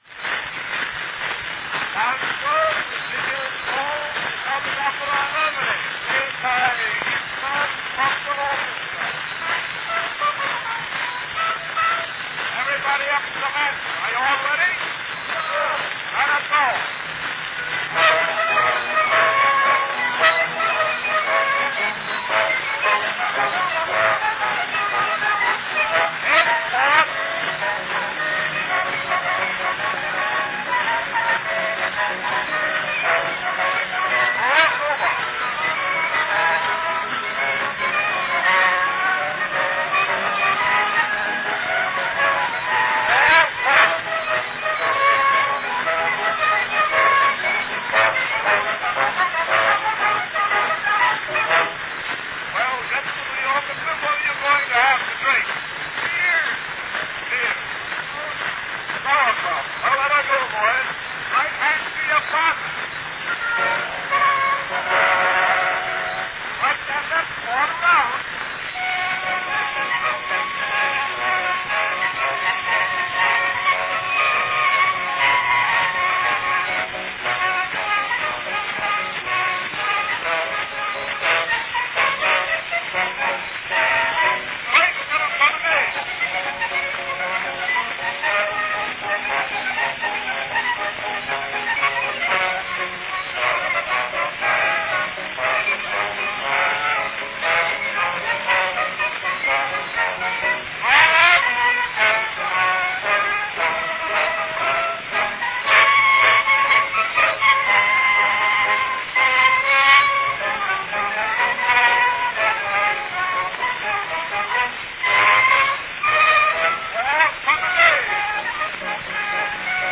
This month:   A popular dance from the 1890's, Lanciers with Figures Called by Issler's Popular Orchestra from 1893.
Category Orchestra
Performed by Issler's Popular Orchestra
This recording was probably made in Newark, New Jersey, and is a "tube copy" – meaning it was copied from the original recording by use of a hollow rubber tube running from the master phonograph to the duplicating phonograph.   Tube copy cylinder recordings have a wonderfully distinctive "hollow" brashness, the quintessentially "tinny" sound associated with early sound recordings.